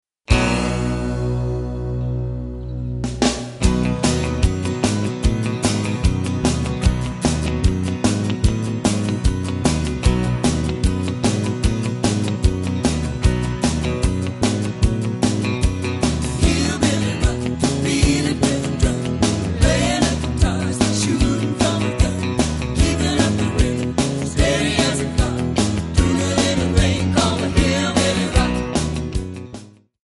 Backing track Karaoke
Country, 1990s